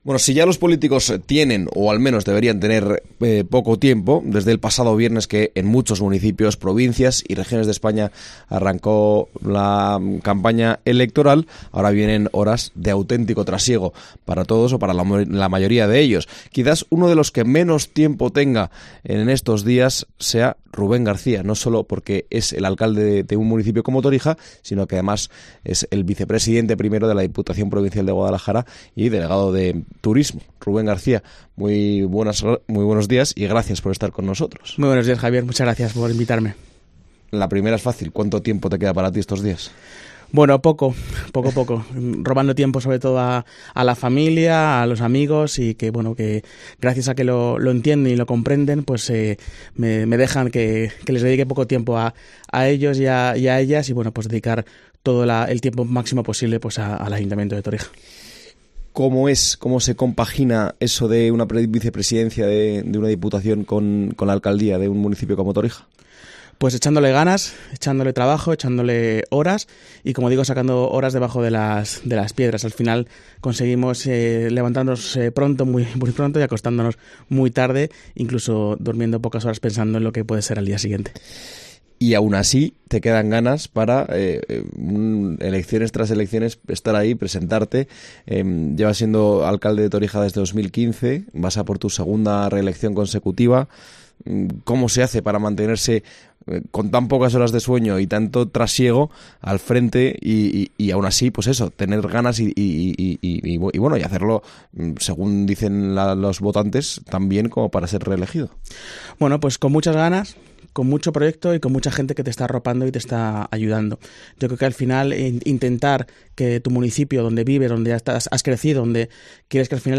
El vicepresidente 1º de la Diputación visita los micrófonos de COPE Guadalajara como candidato a la reelección como alcalde de Torija
Entrevista a Rubén García como candidato a su reelección como alcalde de Torija